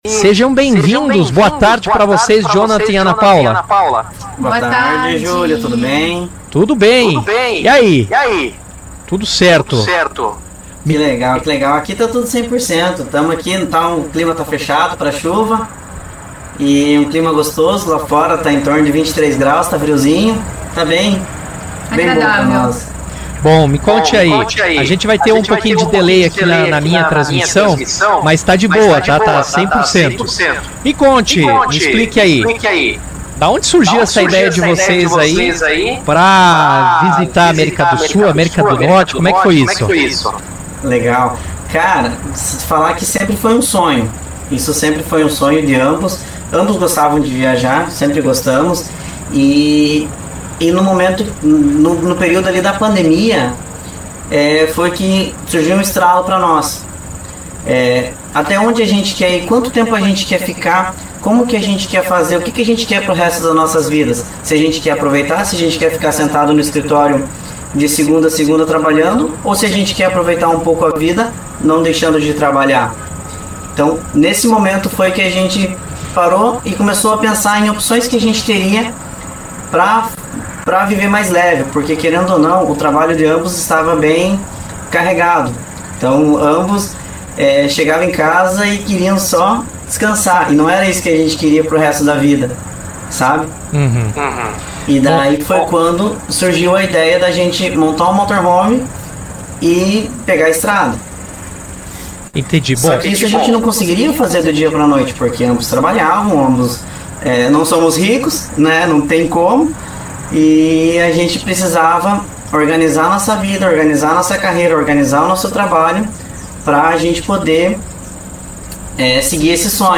O casal concedeu entrevista direto de Torres/RS, ao vivo, no Jornal RA 2ª Edição para falar da aventura em uma Kombi.